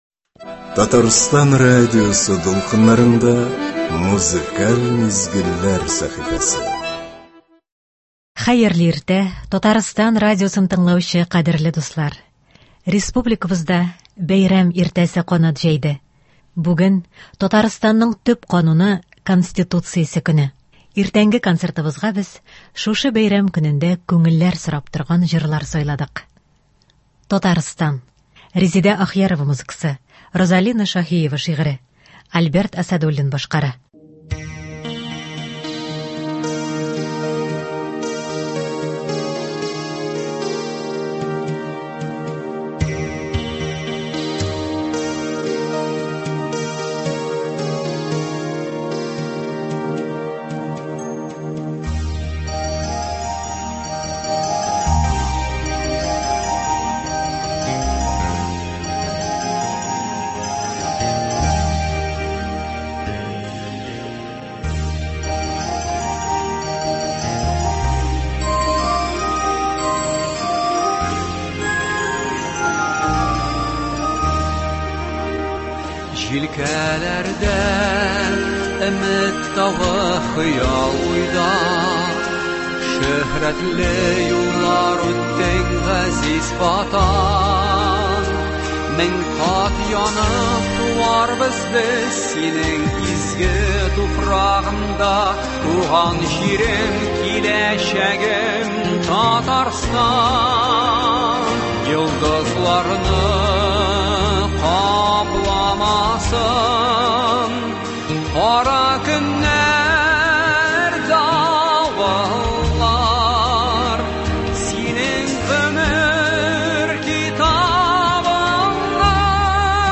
Иртәнге концертыбызга без шушы бәйрәм көнендә күңелләр сорап торган җырлар сайладык.